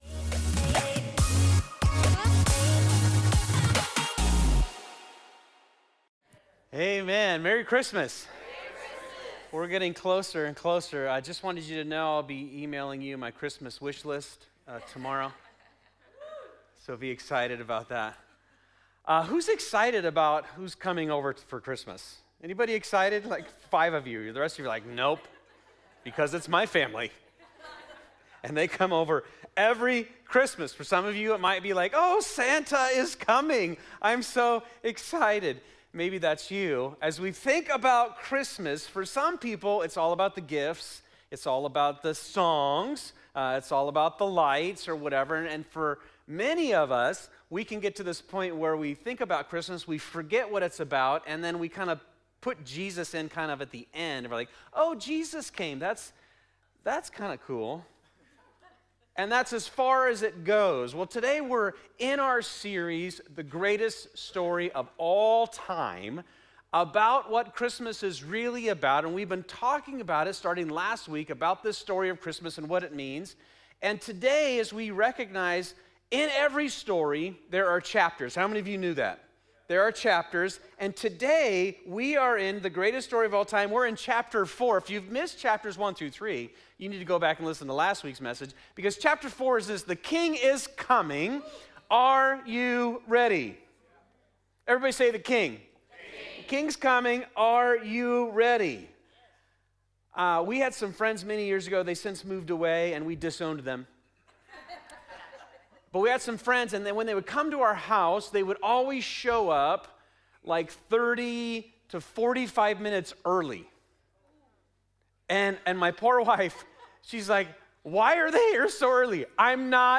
The Greatest Story Of All Time is our Christmas 2025 series at Fusion Christian Church. In this series we look at some of the ways God foreshadowed the Christmas story throughout the bible.